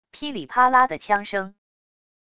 噼里啪啦的枪声wav音频